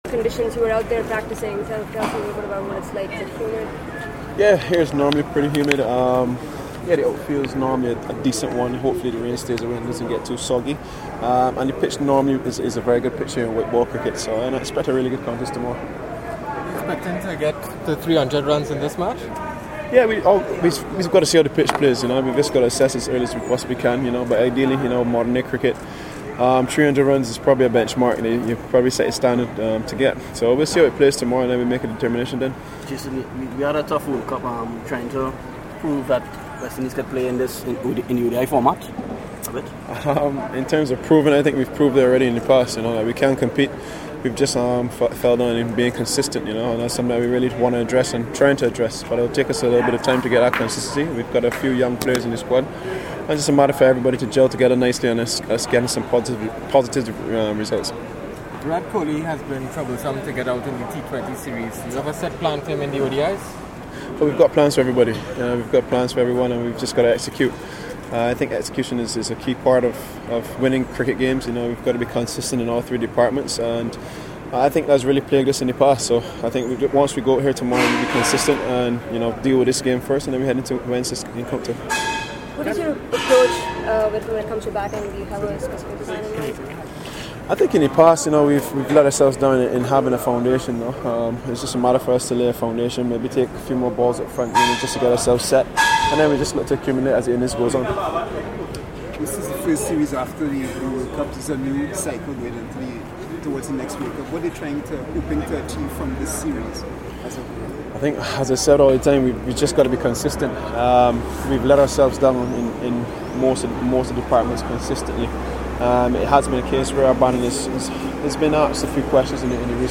West Indies captain Jason Holder spoke to members of the media as the home team prepared for the second ODI against India. The series is the MyTeam11 One-Day International Series, co-sponsored by Skoda and KEI.